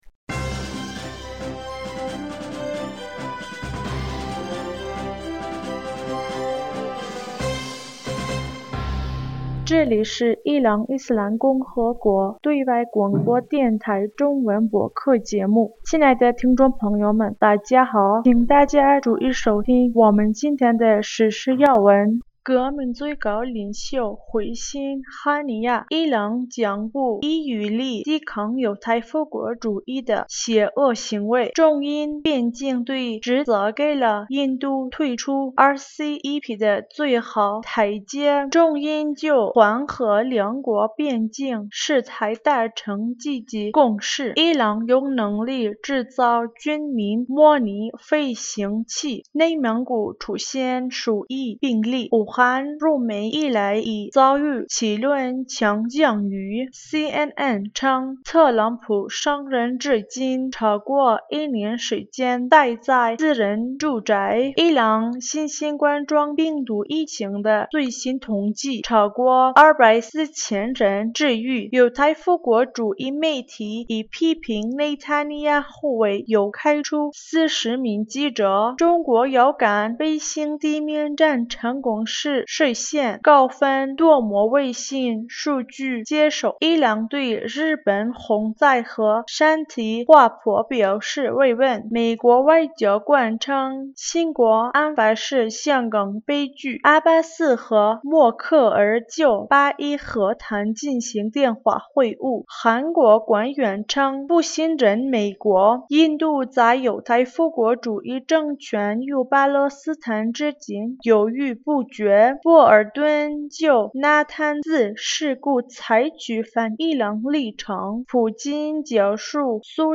2020年7月06日 新闻